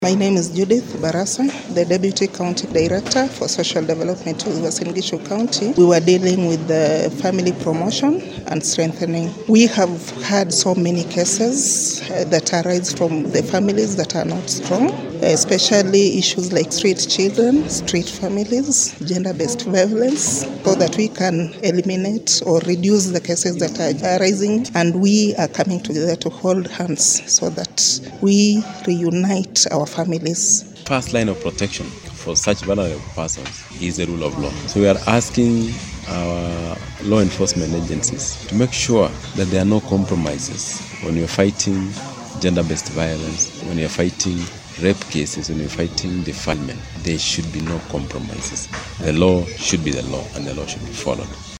SOUND-BITE-ON-FAMILIES-.mp3